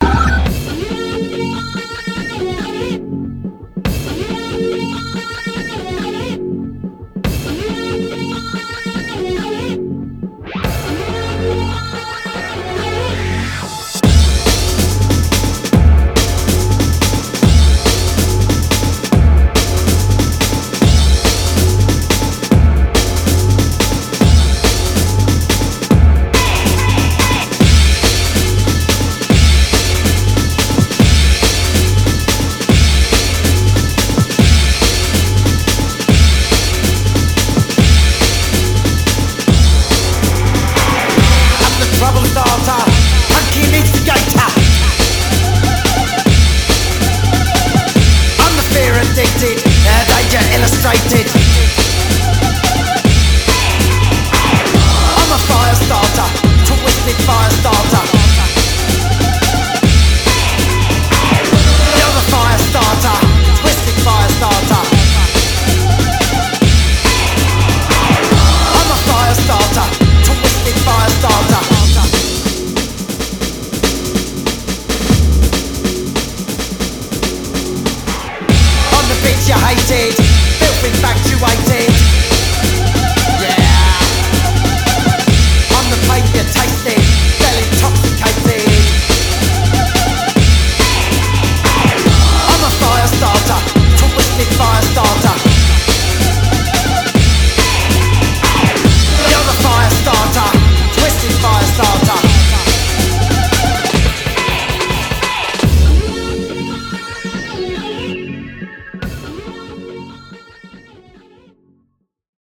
BPM142
Audio QualityMusic Cut